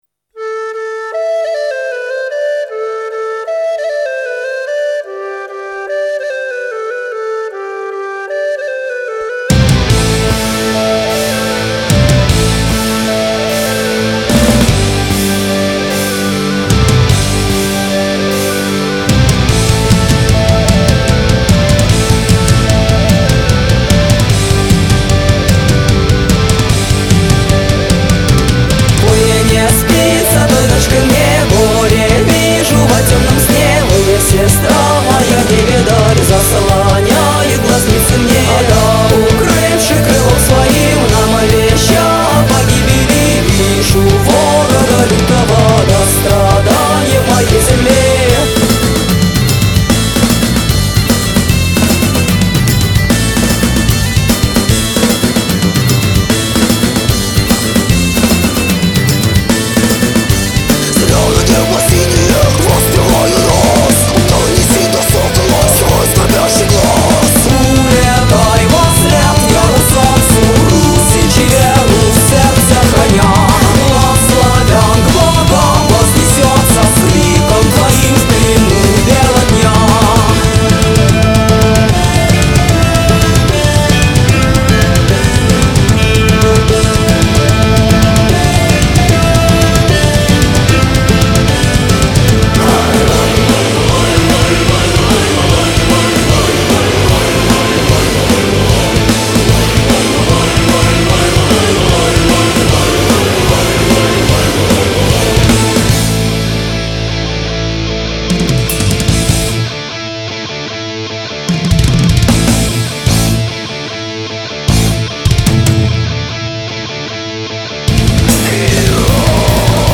Pagan Metal